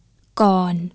kOOn (sounds like 'korn') low tone.